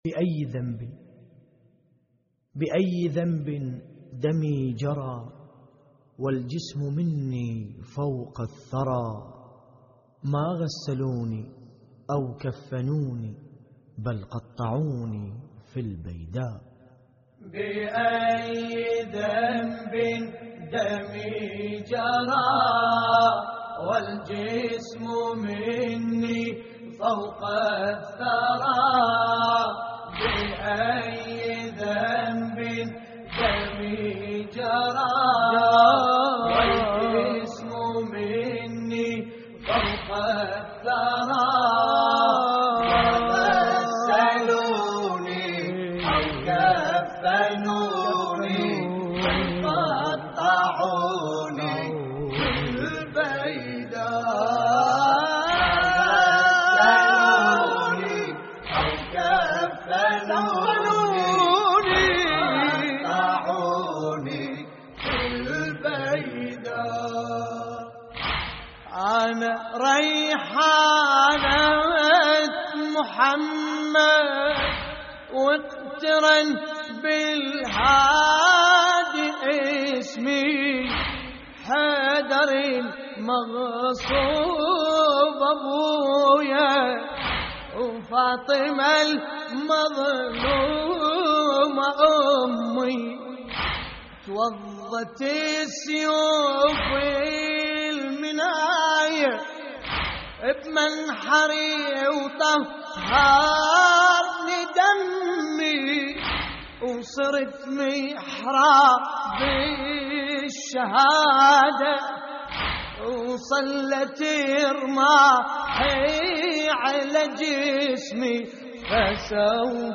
موقع يا حسين : اللطميات الحسينية بأي ذنب دمي جرى - استديو لحفظ الملف في مجلد خاص اضغط بالزر الأيمن هنا ثم اختر (حفظ الهدف باسم - Save Target As) واختر المكان المناسب